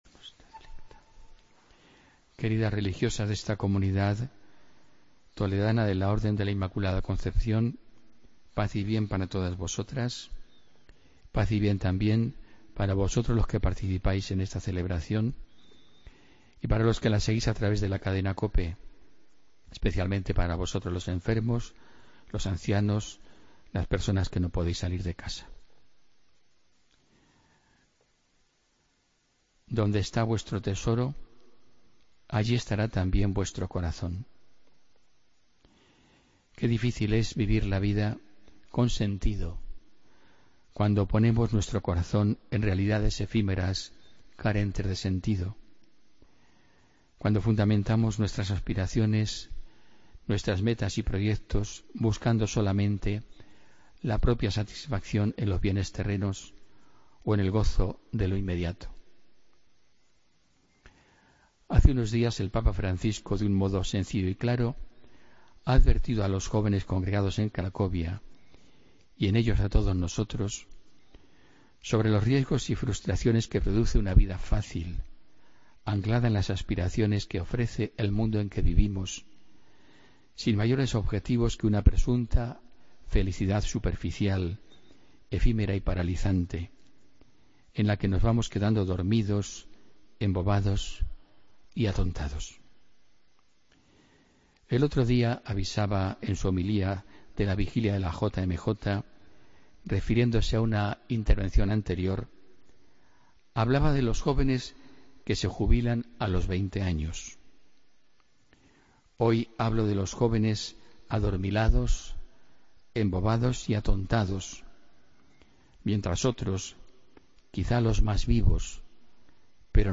Homilía del domingo, 7 de agosto de 2016